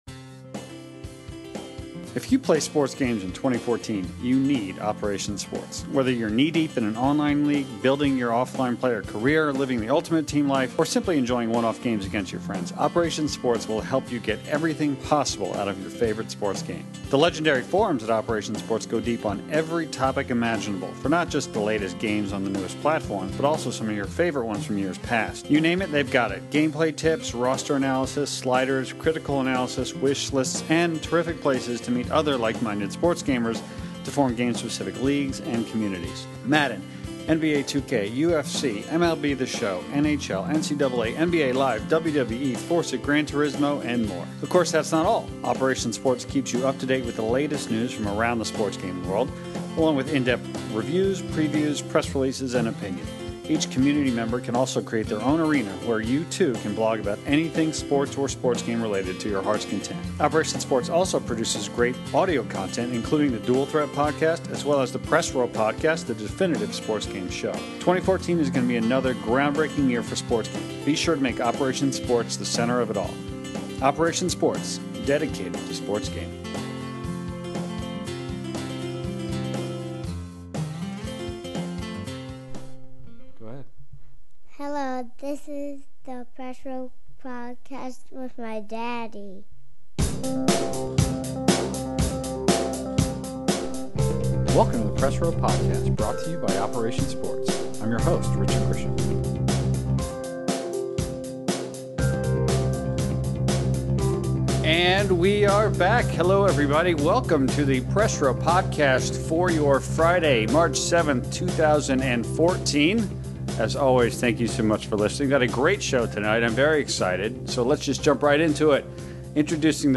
After that, though, the heart of the show deals with a fun conversation about each panelist’s all-time “desert island” single player, couch co-op, and online multiplayer sports games. It’s topped off by the all-time “desert island” sports gaming system.